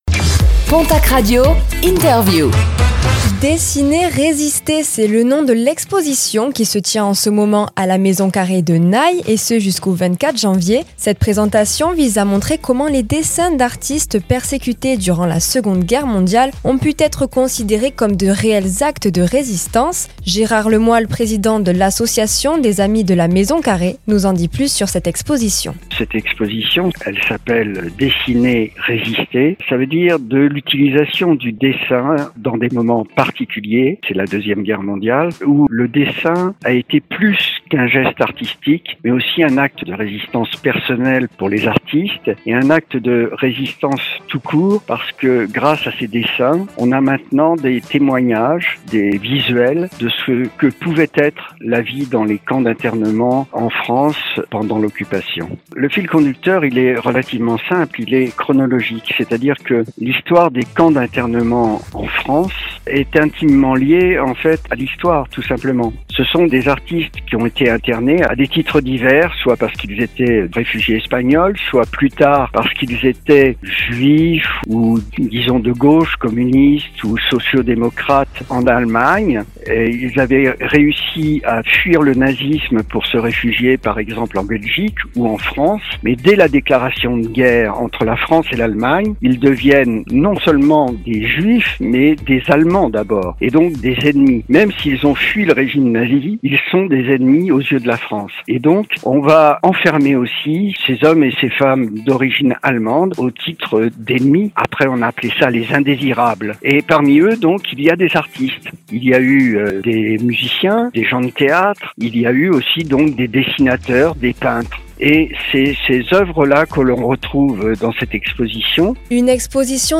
Exposition « Dessiner, Résister » : quand l'art devient un acte de survie - Interview du lundi 19 janvier 2026 - PONTACQ RADIO